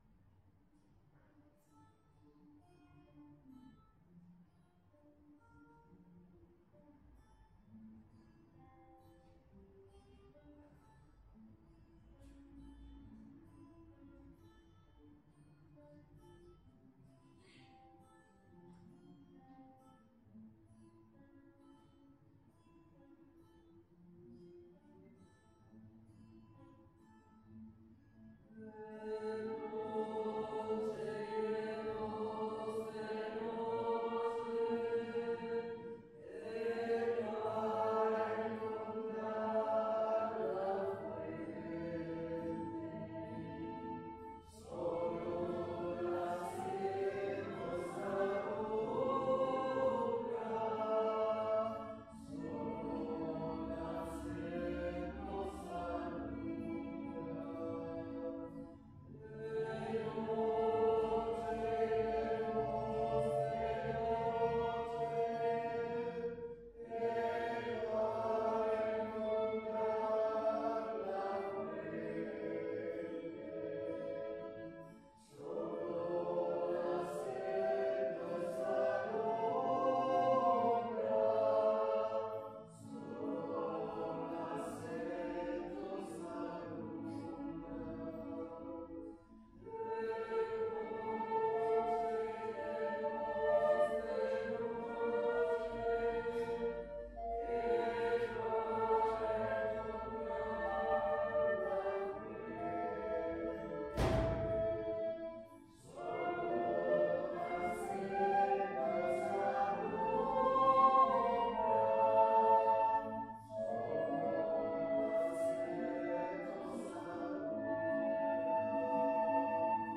Pregària de Taizé a Mataró... des de febrer de 2001
Parròquia de la Sagrada Família - Diumenge 26 de juny de 2016
Vàrem cantar...